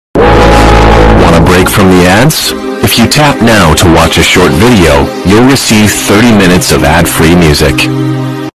You Wanna Break From The Ads Earrape